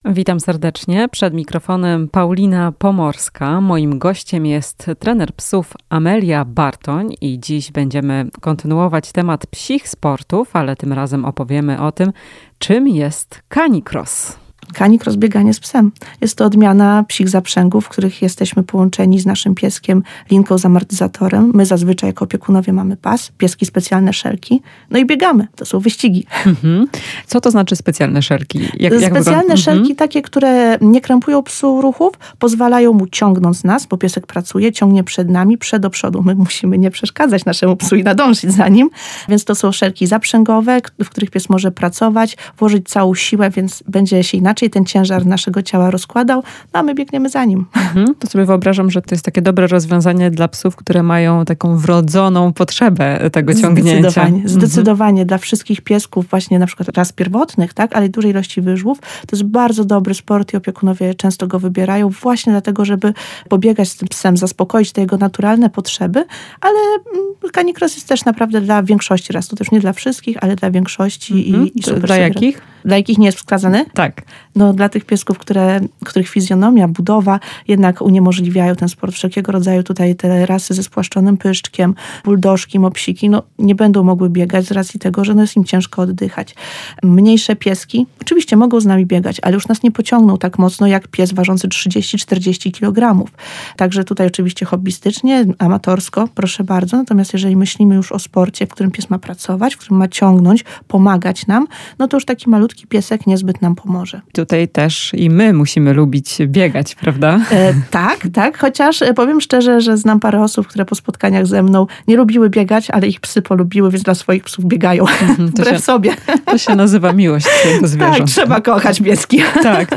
W "Chwili dla pupila" omawiamy psi sport, jakim jest CaniCross. Rozmowa z trenerem psów